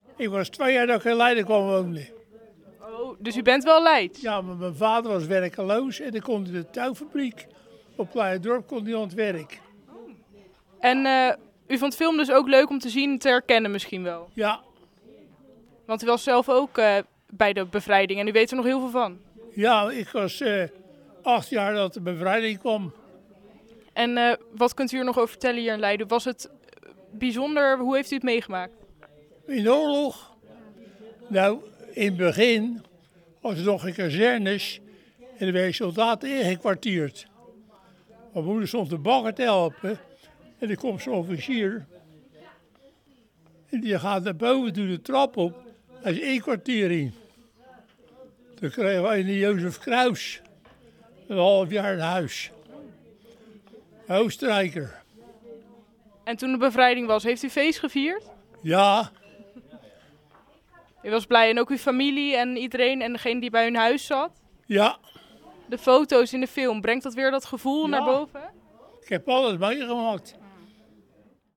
spreekt met een bewoner van het verzorgingstehuis die zich de bevrijding in Leiden nog kan herinneren